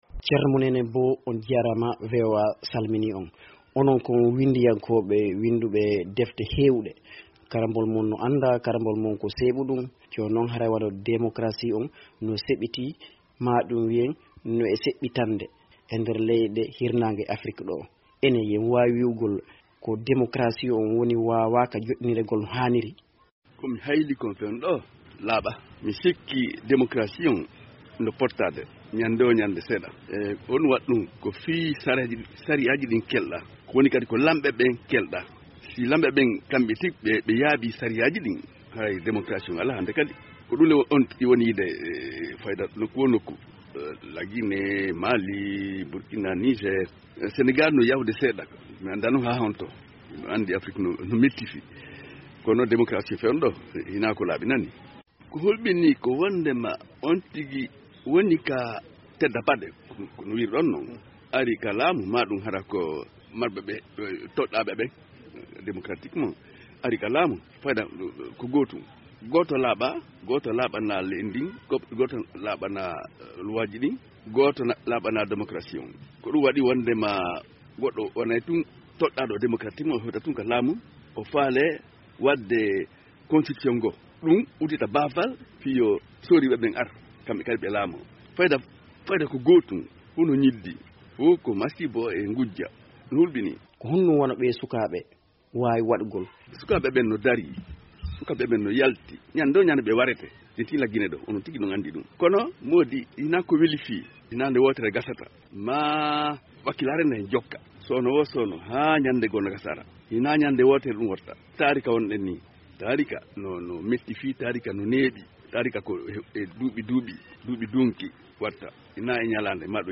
Ko Cerno Monenembo woni koɗo Daande Fulɓe ndee yontere ɗoo. Cerno Monenembo ko winndiyankeejo Ginenaajo lolluɗo. ko kanko yeɗanoo mbuuñaari “Renodo 2008” sabu ndee deftere makko “Laamɗo Kahel”.